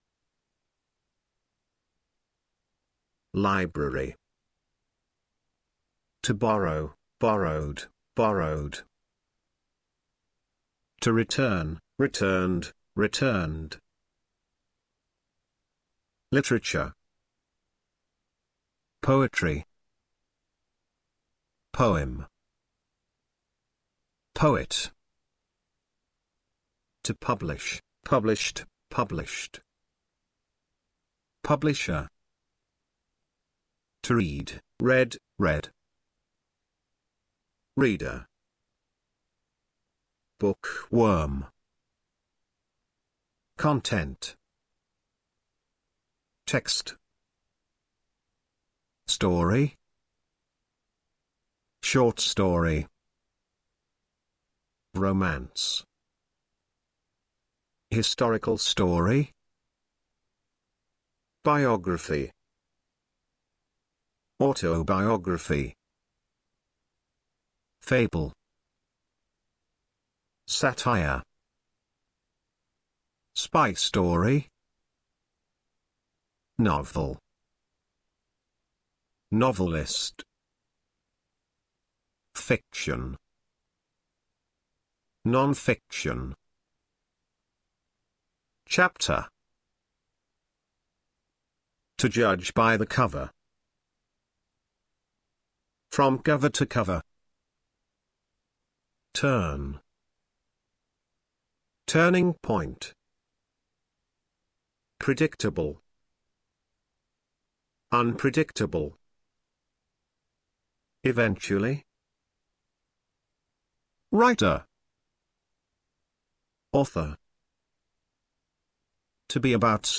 Hallgasd meg a szavak kiejtését az ikonra kattintva.